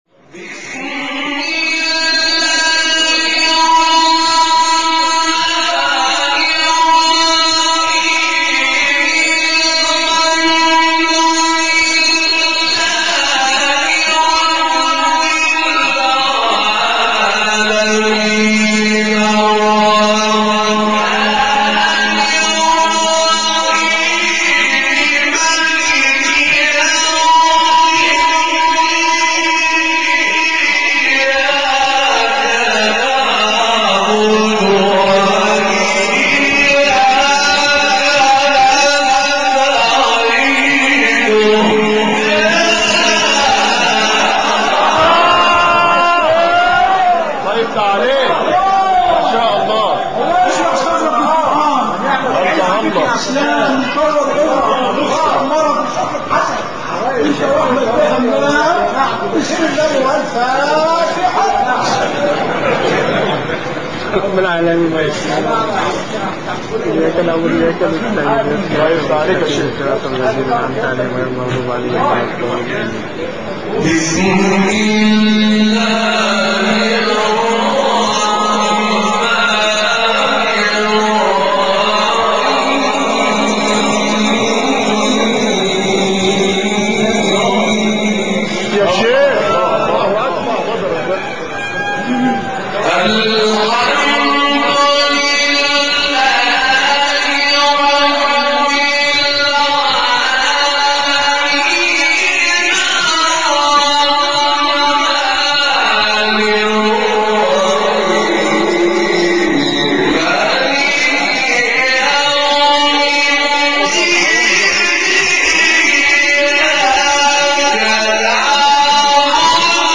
تلاوت سوره حمد استاد طاروطی | نغمات قرآن | دانلود تلاوت قرآن